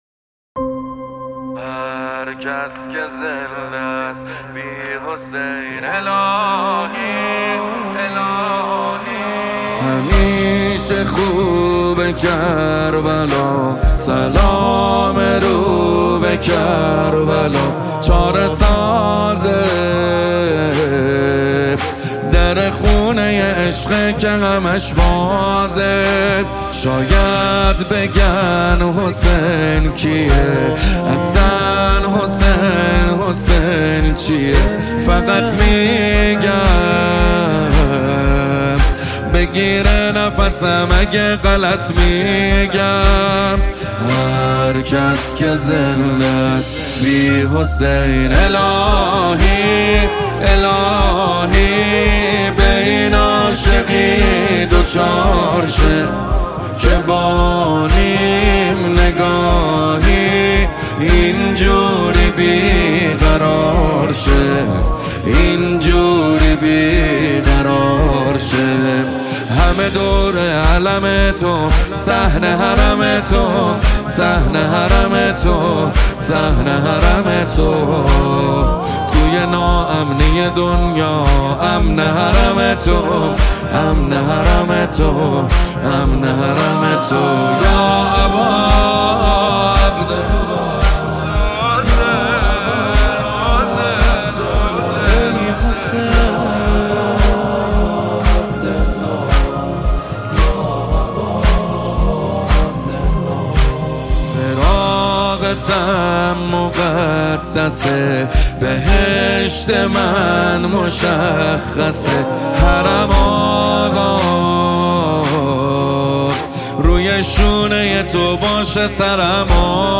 دانلود مداحی
با نوای دلنشین